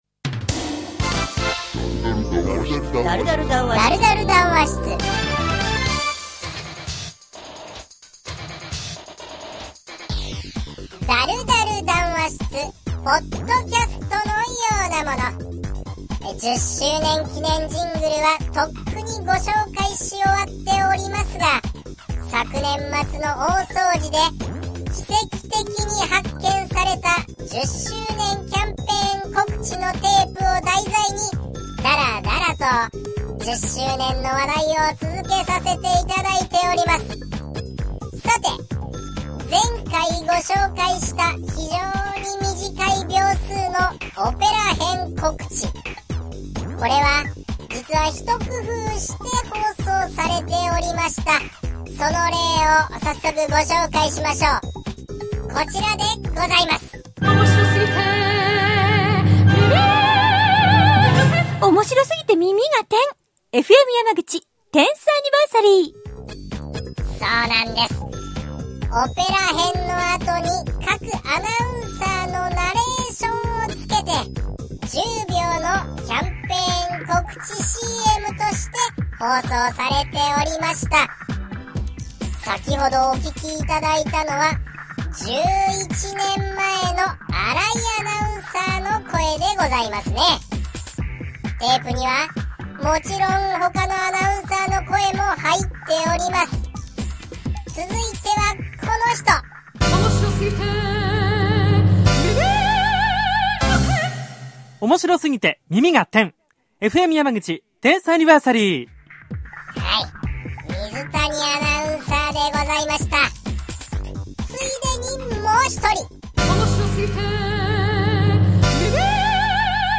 10周年キャンペーンのオペラ。放送用にナレーションが付いた完成版。